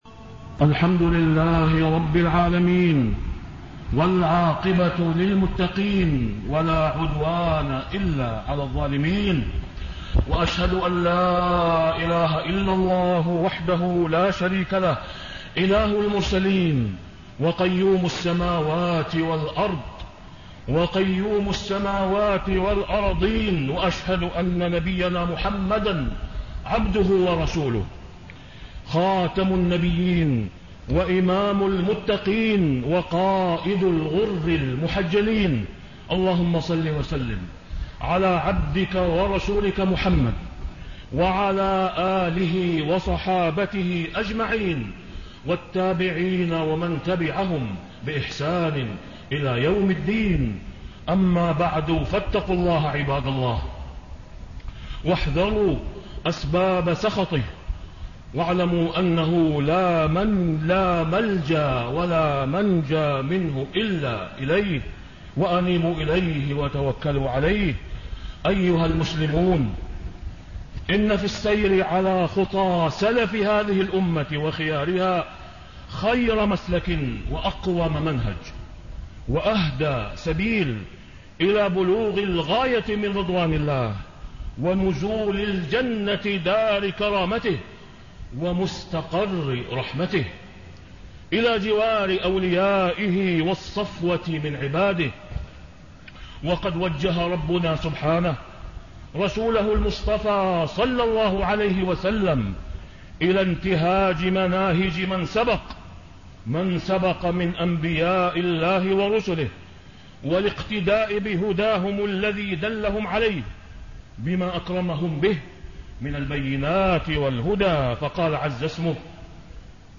تاريخ النشر ٢٠ ذو الحجة ١٤٣٤ هـ المكان: المسجد الحرام الشيخ: فضيلة الشيخ د. أسامة بن عبدالله خياط فضيلة الشيخ د. أسامة بن عبدالله خياط التحذير من إيذاء المسلمين